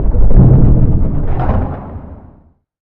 moontruckwork2.ogg